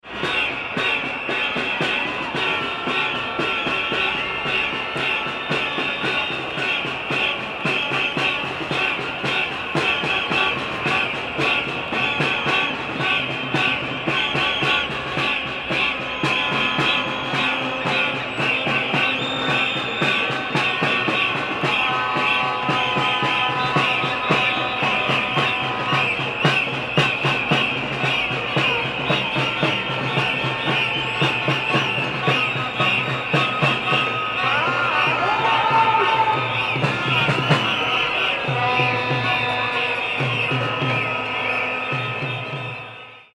Intense Protest Crowd Sound Effect
Description: Intense protest crowd sound effect.
Hear thousands of people protesting on a city street, banging drums, whistling, honking, and shouting.
Intense-protest-crowd-sound-effect.mp3